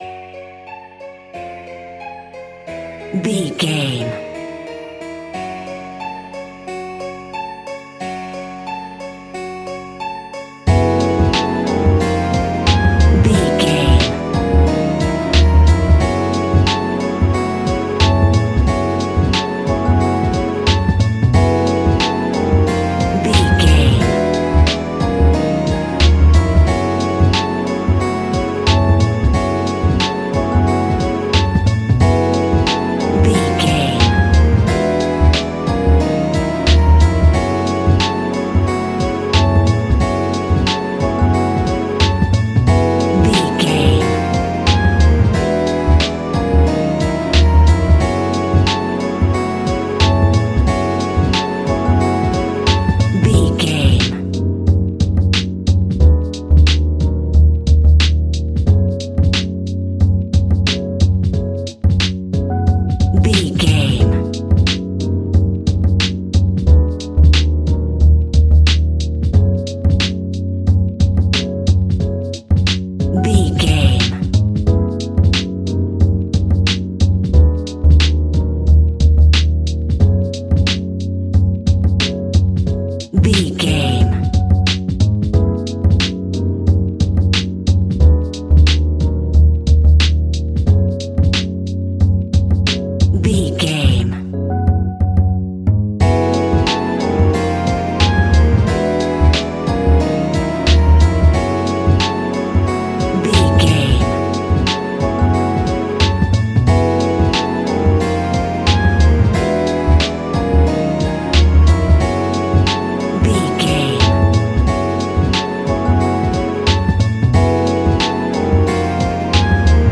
Ionian/Major
F♯
laid back
Lounge
sparse
new age
chilled electronica
ambient
atmospheric
instrumentals